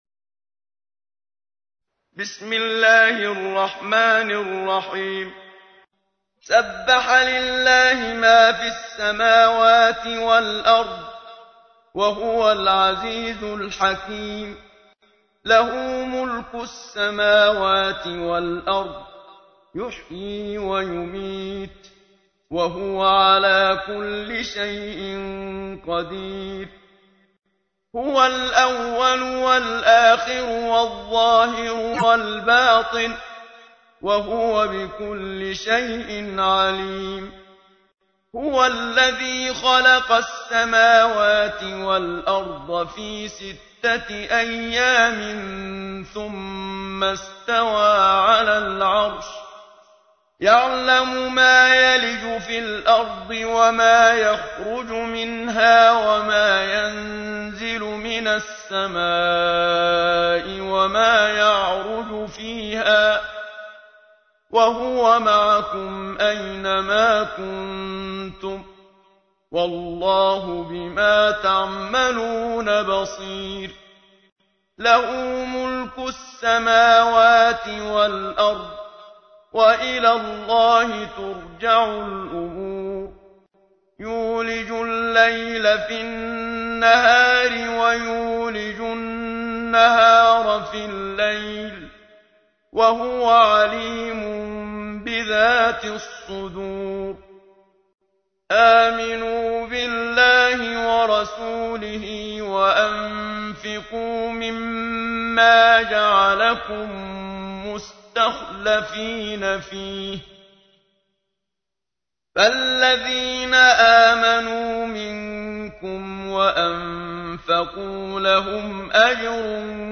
تحميل : 57. سورة الحديد / القارئ محمد صديق المنشاوي / القرآن الكريم / موقع يا حسين